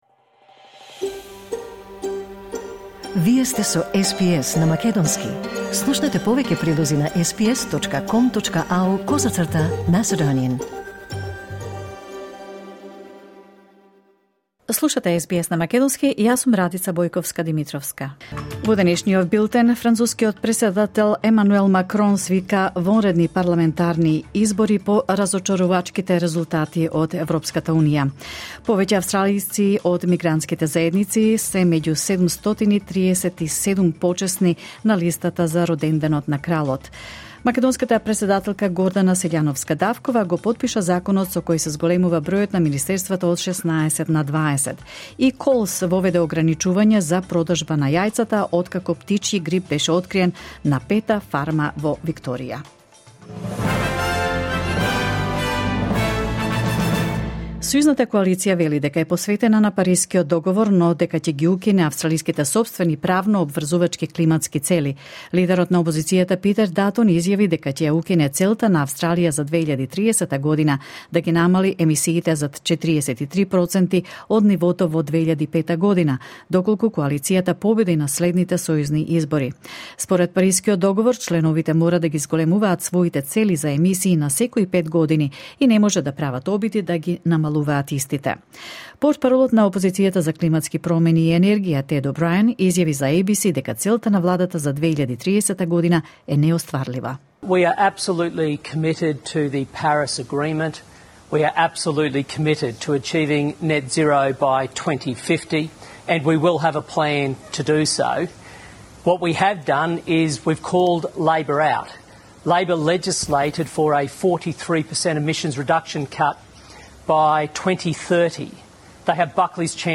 SBS News in Macedonian 10 June 2024